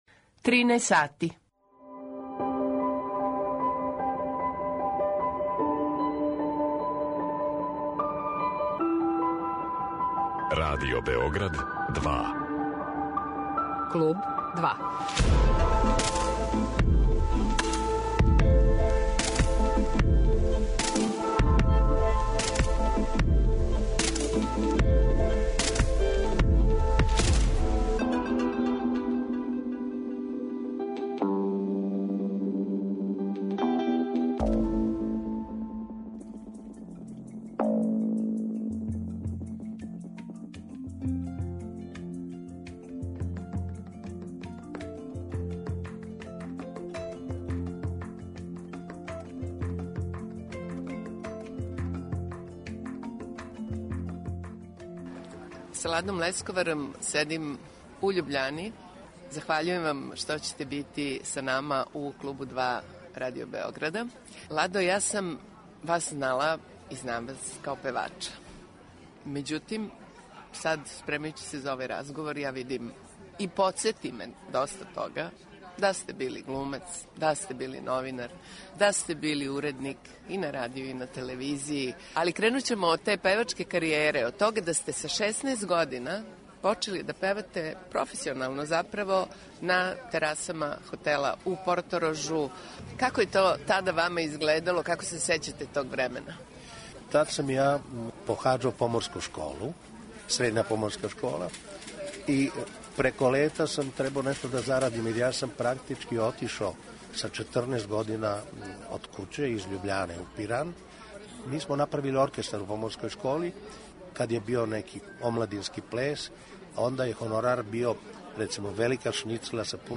Пева и данас, непромењеним гласом. У Клубу 2 приповеда нам свој више него занимљив живот.